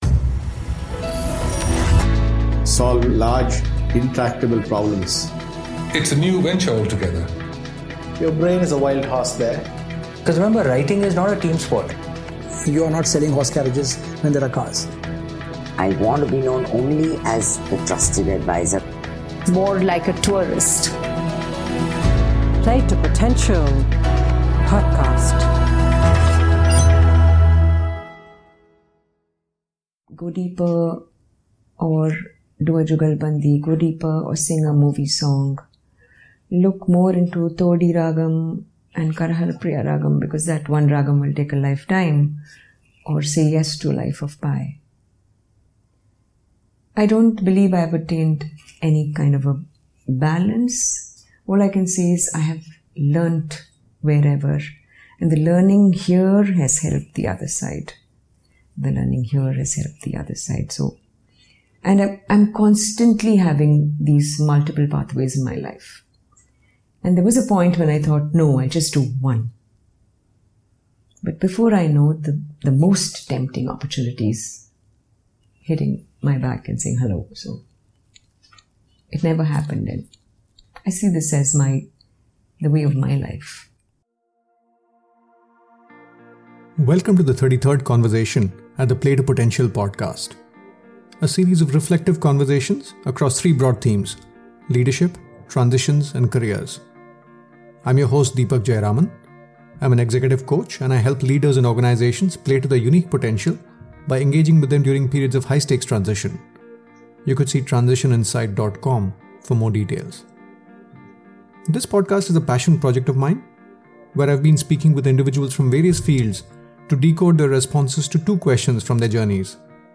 Bombay Jayashri is one of the leading Carnatic musicians in the country.